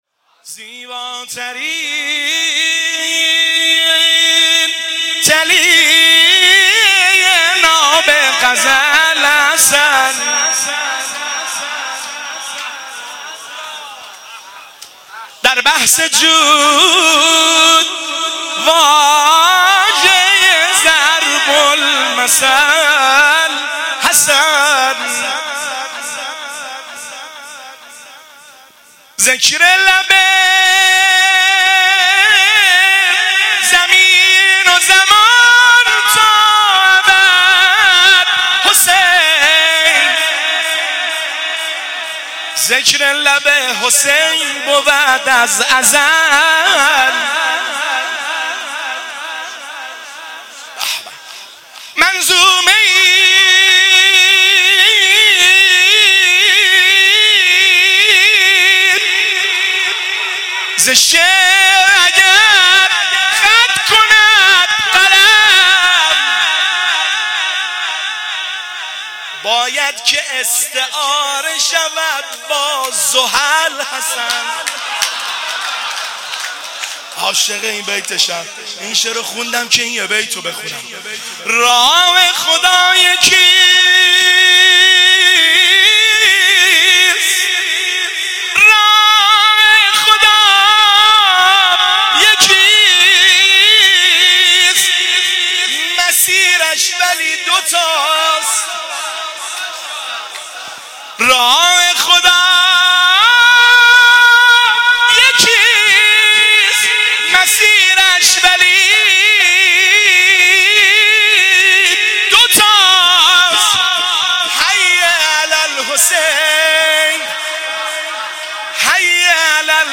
دانلود نوحه شهادت امام حسن مجتبی